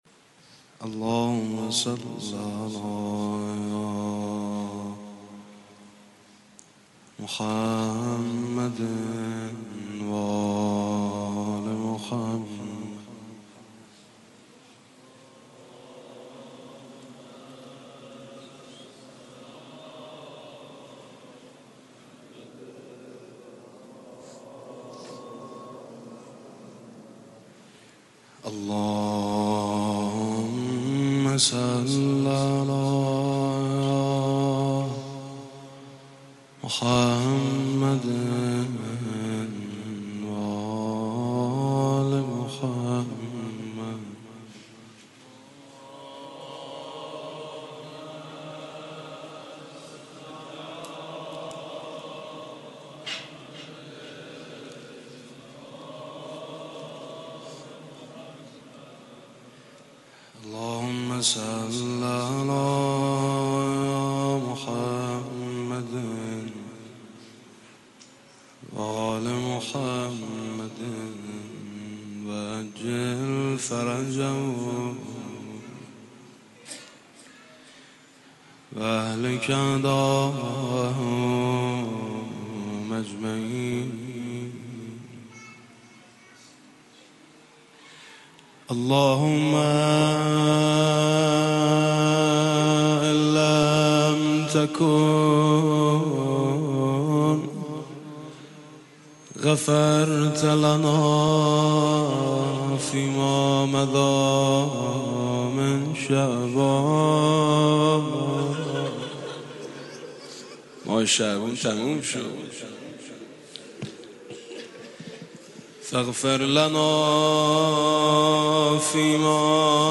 صوت/ مناجات شعبانیه با نوای حاج میثم مطیعی
مناجات شعبانیه با نوای حاج میثم مطیعی به مناسبت فرا رسیدن ماه شعبان المعظم